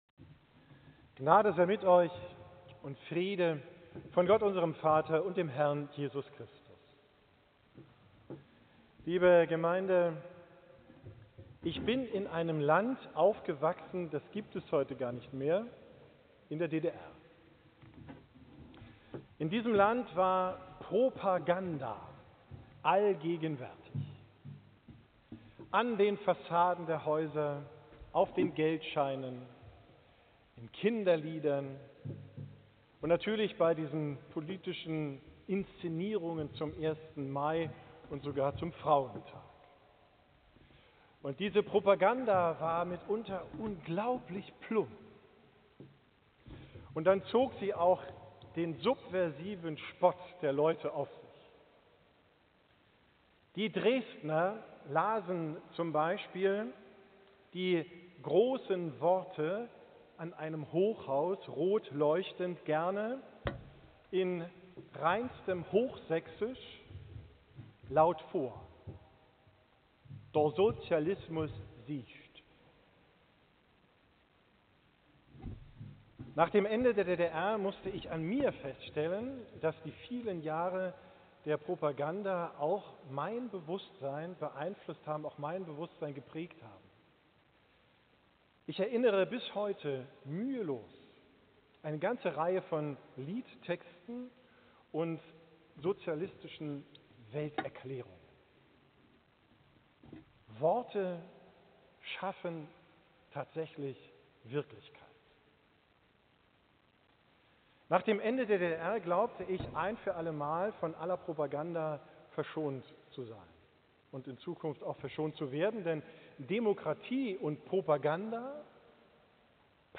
Predigt vom Sonntag Jubilate, 26.